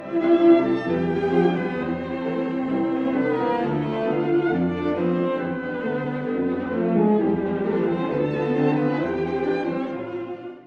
舞曲風の最終楽章です。
この旋律はロンド風に繰り返されて、時には「祭り」の雰囲気にもなります。